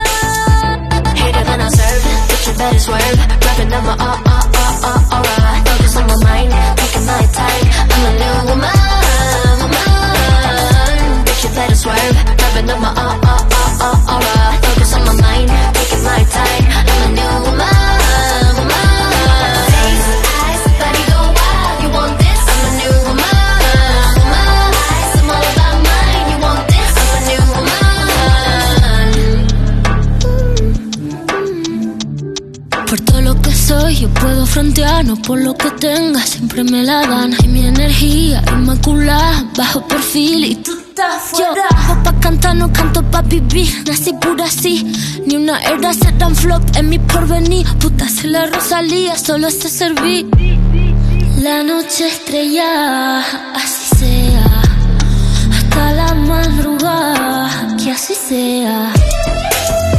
[8D AUDIO] 🎧USE HEADPHONES🎧